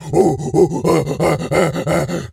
pgs/Assets/Audio/Animal_Impersonations/gorilla_chatter_08.wav at master
gorilla_chatter_08.wav